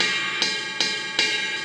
RIDE_LOOP_11.wav